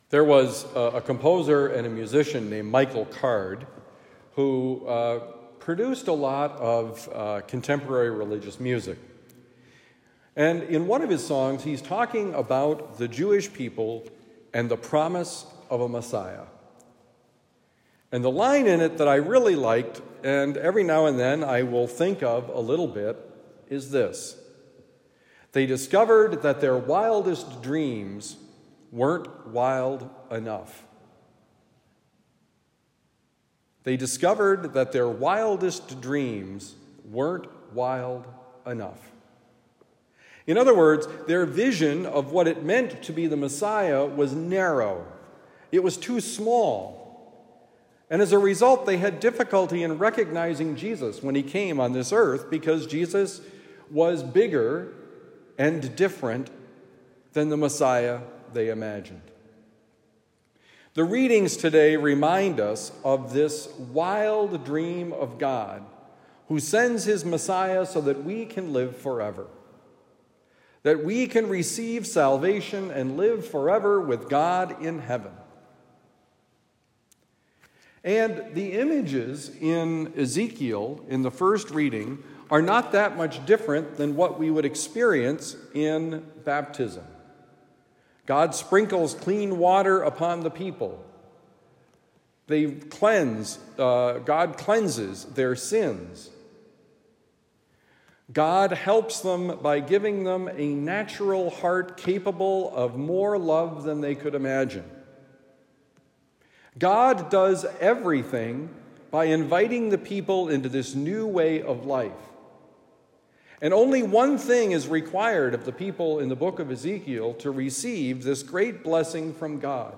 The dreams are not wild enough: Homily for Thursday, August 22, 2024